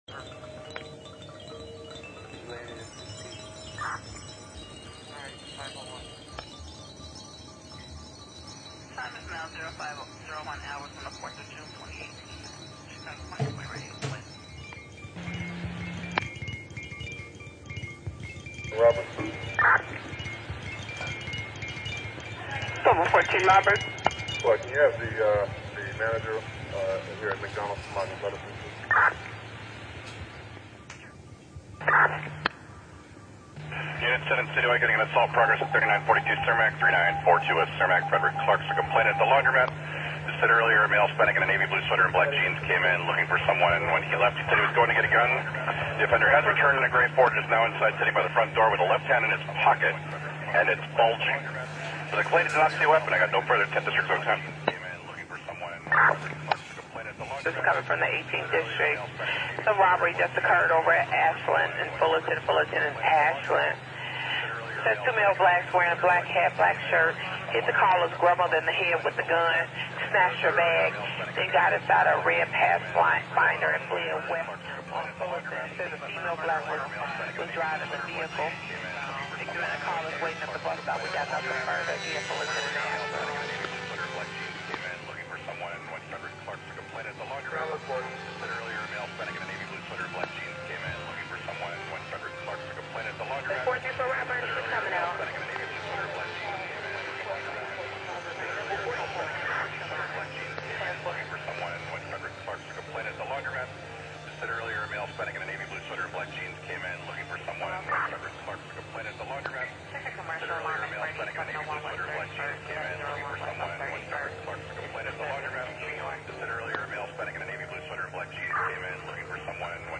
Radio Concrete is a monthly experimental radio show focusing on sounds we’re exposed to every day in the public and domestic spheres - using field recordings, amplified and toys and live sources such as am/fm radio.